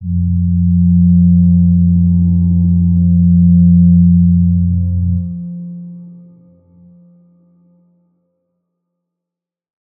G_Crystal-F3-pp.wav